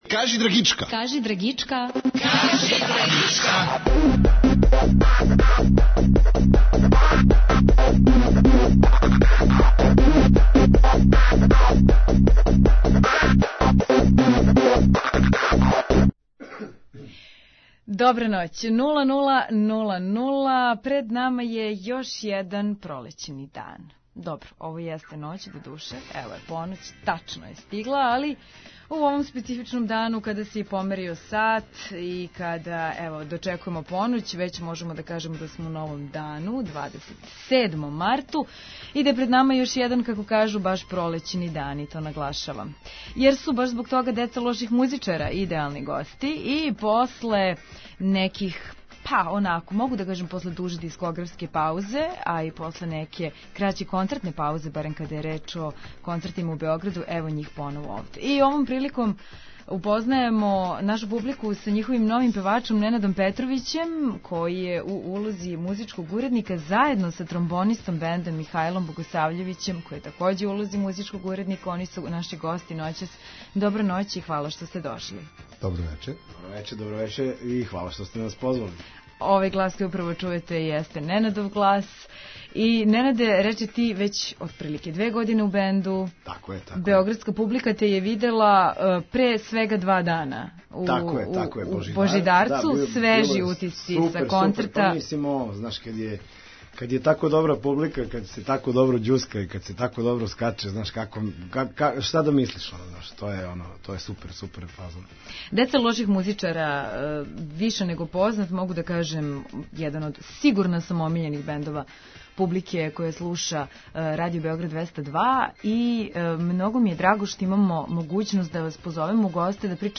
Стога су "Деца лоших музичара" идеални гости. После дуже концертне и дискографске паузе поново активни и орни за рад.